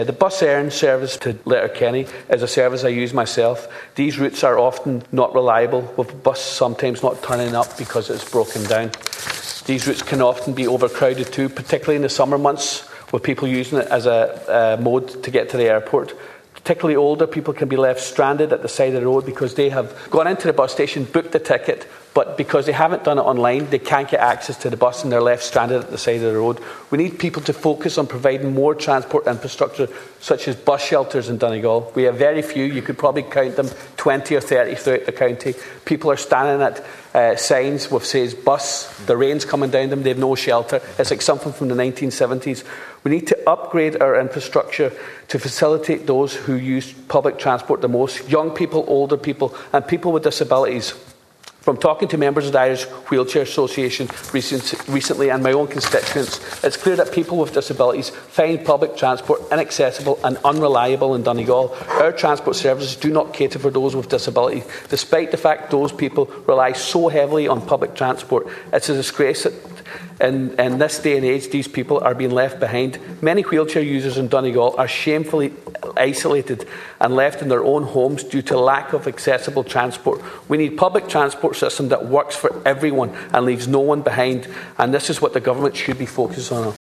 Speaking during a Dail debate on the public transport experience today, Deputy Ward said the lack of a rail network contributes to chronic traffic issues in Letterkenny and other areas of Donegal.